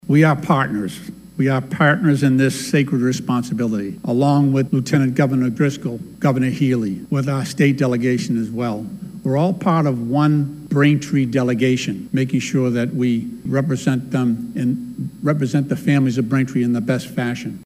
Congressman Stephen Lynch also spoke during the ceremony, pledging to work with Joyce to further the interests of the community.